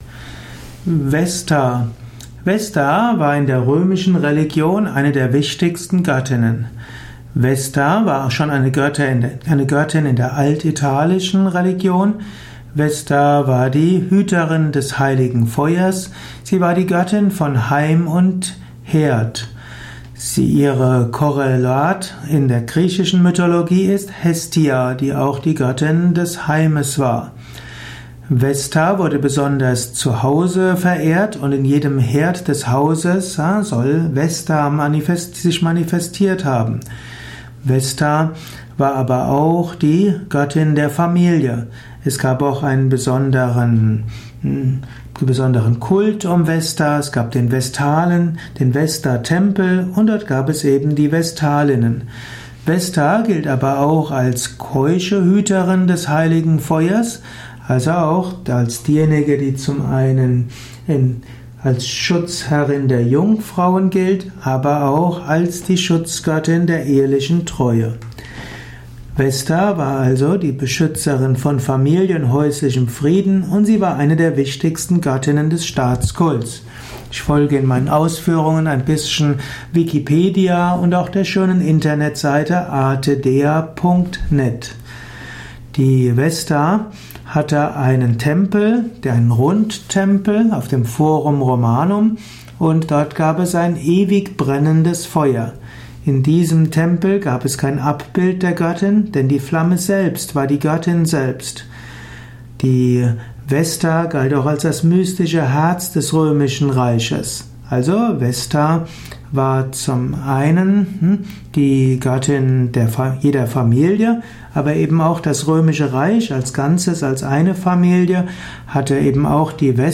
Dies ist die Tonspur eines Videos, zu finden im Yoga Wiki.